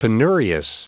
Prononciation du mot penurious en anglais (fichier audio)
Prononciation du mot : penurious